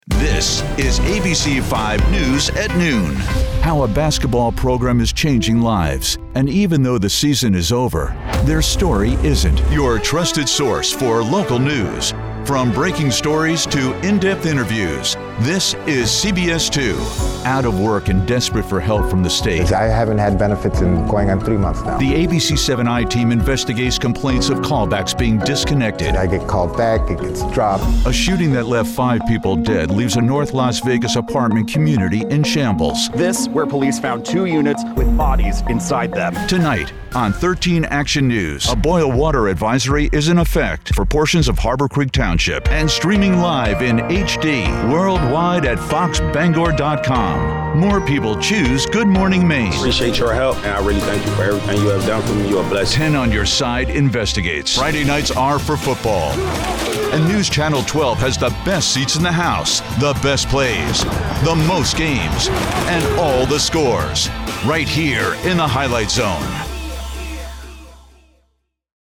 Male
Adult (30-50), Older Sound (50+)
His voice is like warm butter, smooth, professional, and articulate, yet approachable.
Radio / TV Imaging
Words that describe my voice are Warm, Authoritative, Approachable.